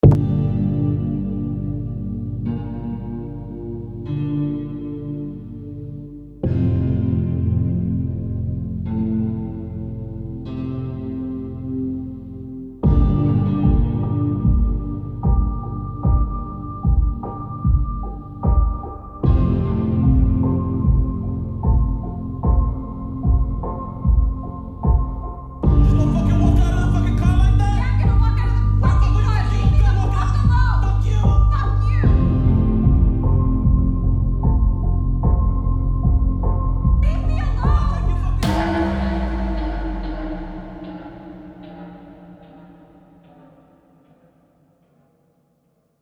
On a décidé de faire des musiques ambiantes qui pourraient coller avec un court métrage ou bien un film.